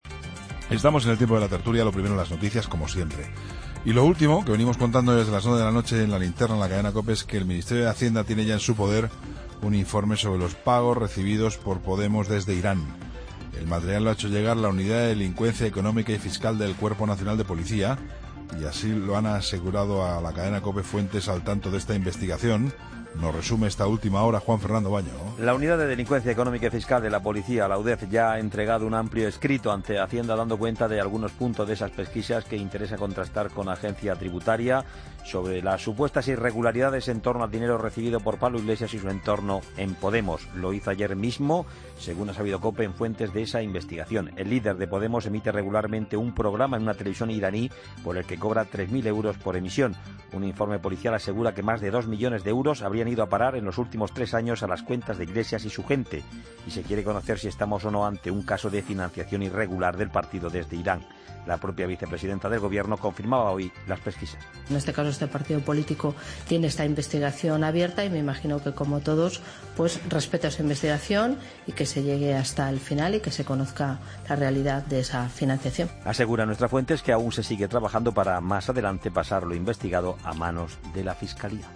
AUDIO: Crónica